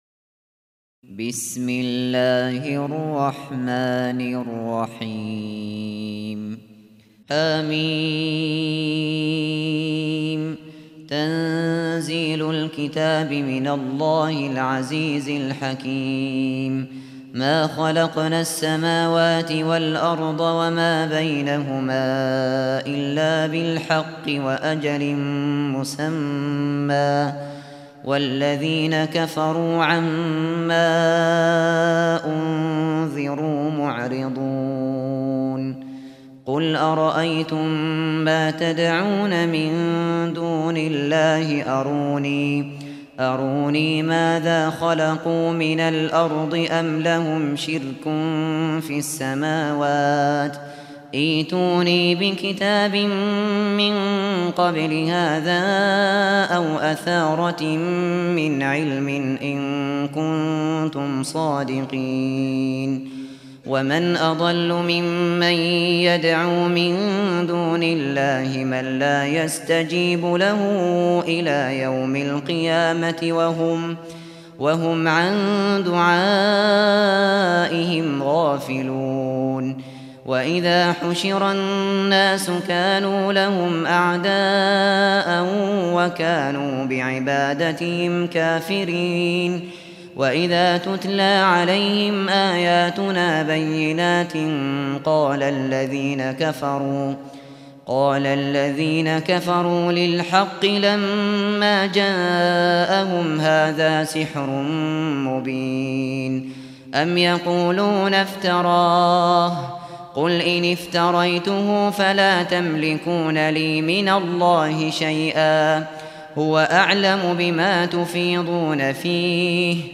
شبكة المعرفة الإسلامية | القران | سورة الأحقاف |أبو بكر الشاطري